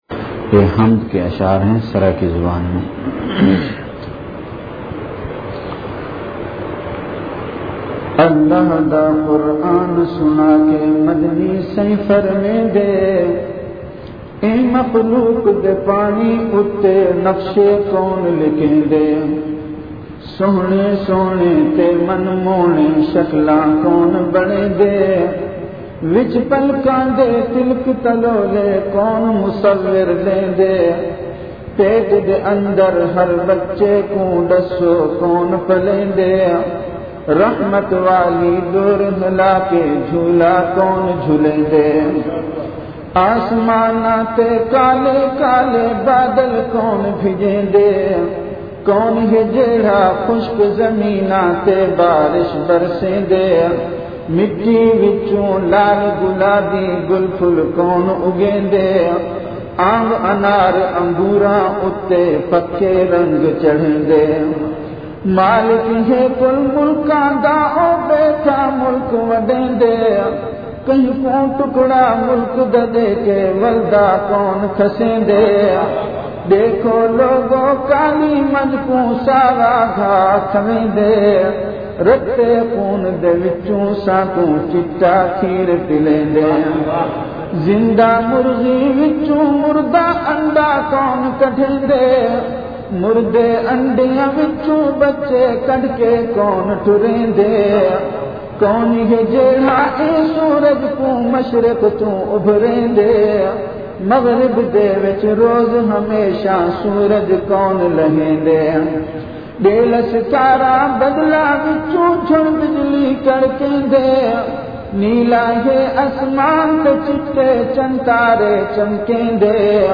Majlis-e-Zikr
Venue Home Event / Time After Isha Prayer